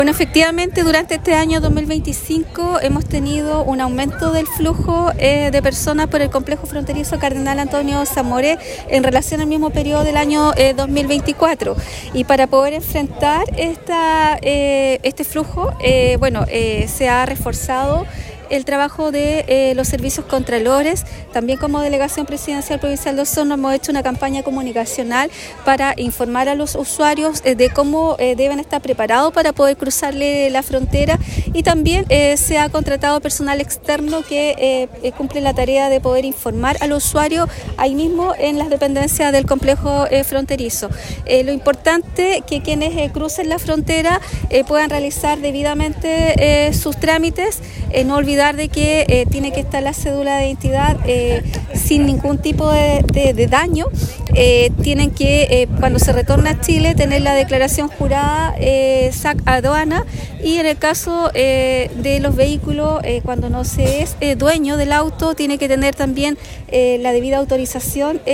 La Delegada Presidencial Provincial de Osorno, Claudia Pailalef, destacó que este aumento en el flujo de personas ha superado las cifras de la misma fecha del 2024, por lo que se han reforzado los trabajos con los servicios contralores. En este contexto, la autoridad hizo un llamado a los usuarios a asegurarse de llevar la documentación requerida para cruzar la frontera.